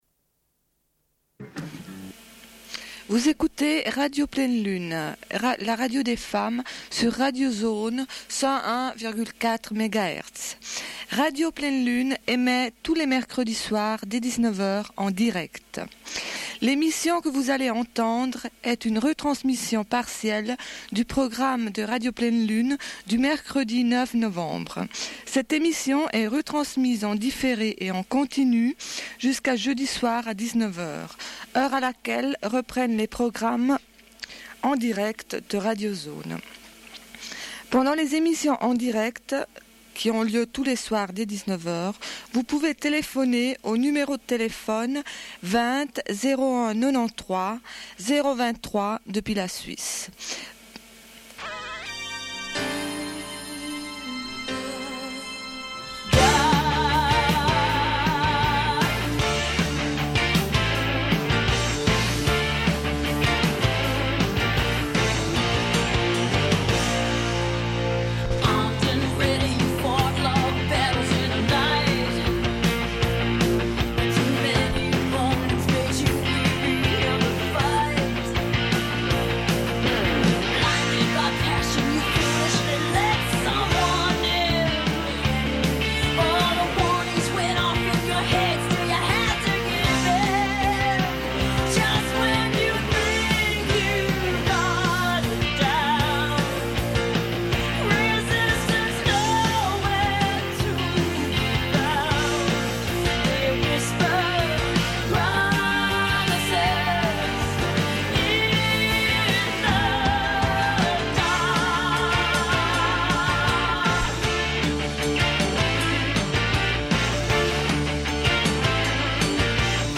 Une cassette audio, face B46:51
00:23:05 // Téléphone avec une personne qui assisté au procès pour le viol de deux femmes à Valence. // 00:40:15